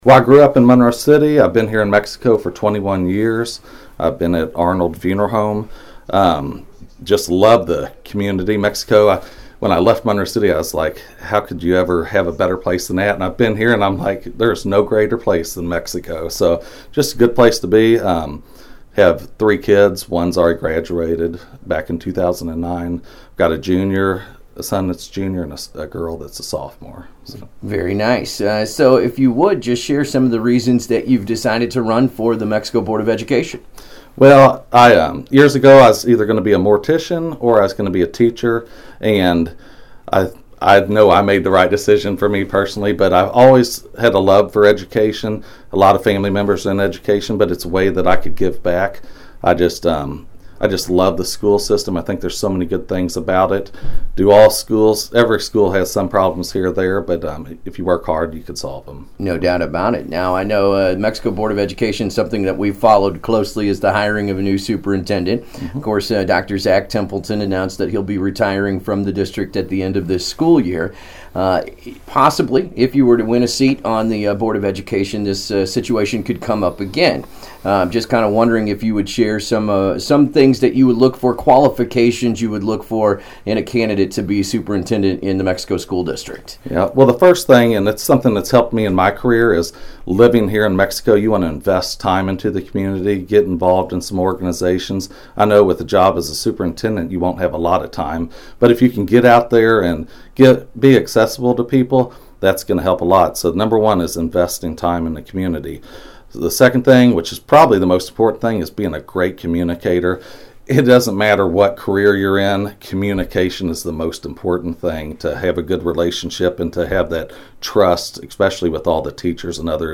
AM 1340 KXEO and the Am I Awake Morning Show will be interviewing these candidates individually over the next several weeks.